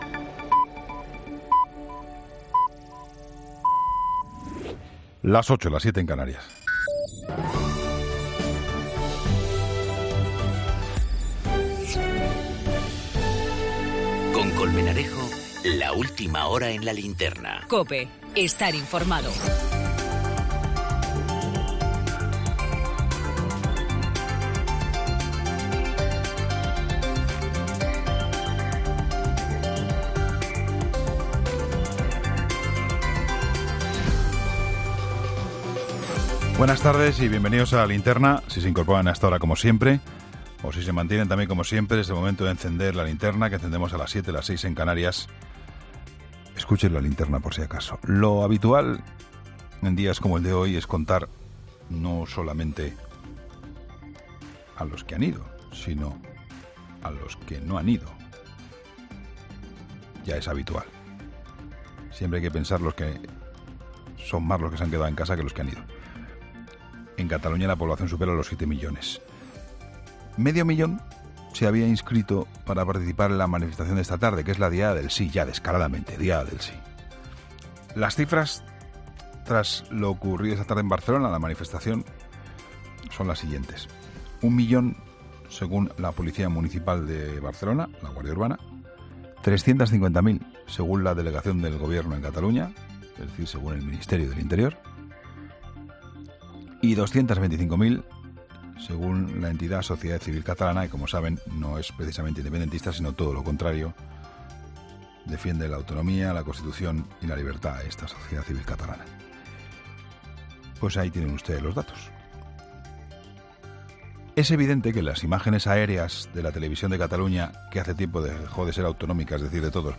La crónica